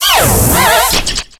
Cri de Scorvol dans Pokémon X et Y.